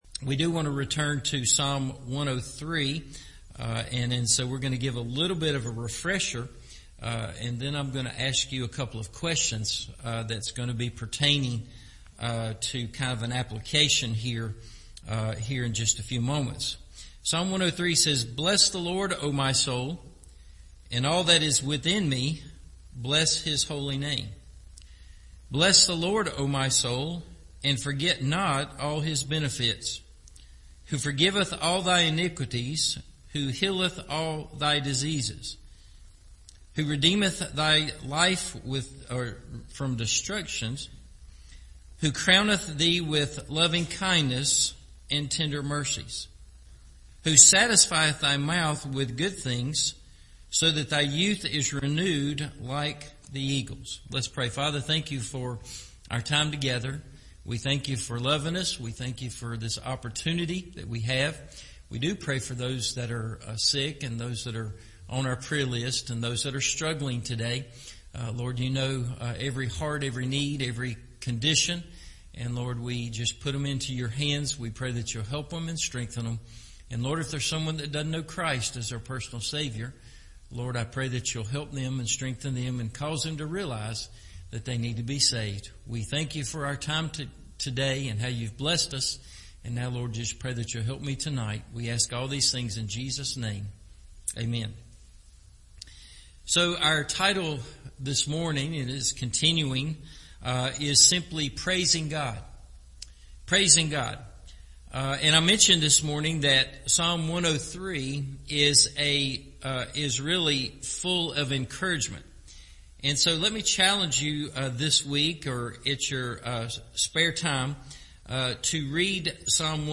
Praising God – Part 2 – Evening Service